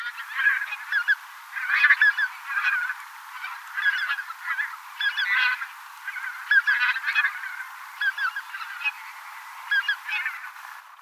tundrahanhen ääntelyä,
taustalla metsähanhi
tundrahanhen_aantelya_taustalla_metsahanhilintu.mp3